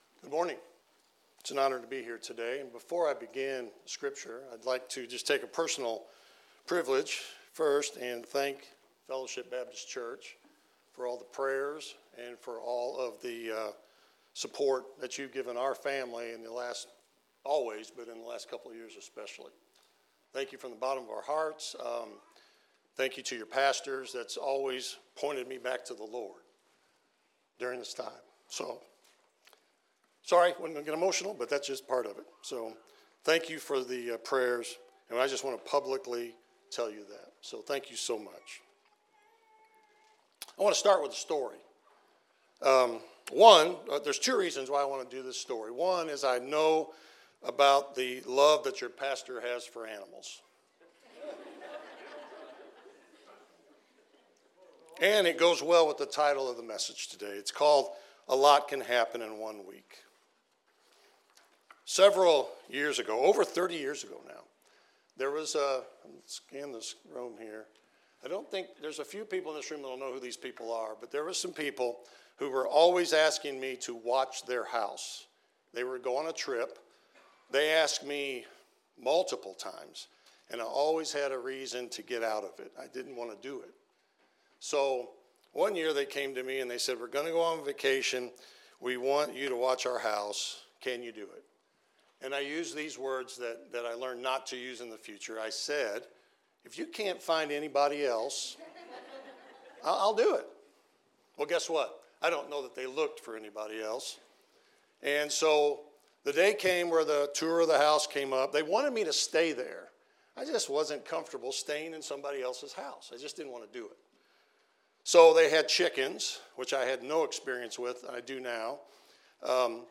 2025 A Lot Can Happen in a Week Preacher: Guest Speaker
Passage: Matthew 21:1-11 Service Type: Sunday Morning Related Topics